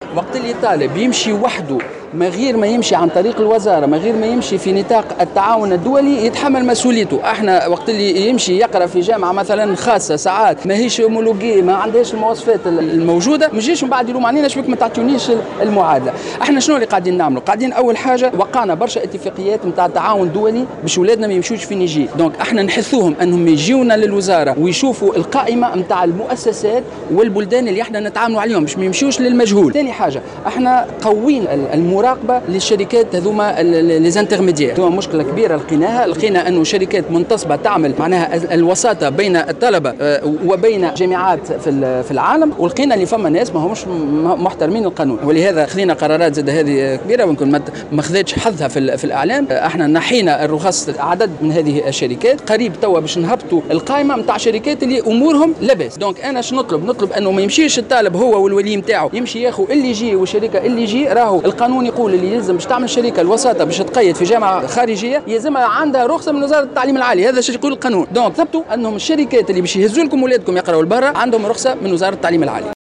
دعا وزير التعليم العالي والبحث العلمي سليم خلبوس في تصريح لمراسلة الجوهرة "اف ام"، لدى إشرافه رفقة وزير التربية على، افتتاح المنتدى الوطني للتوجيه الجامعي والمهن الطلبة الجدد الراغبين في الدراسة بالخارج وعائلاتهم، إلى التثبت من اعتماد الجامعات الأجنبية وطبيعة الشهادات التي تسندها وشروط الحصول على المعادلة.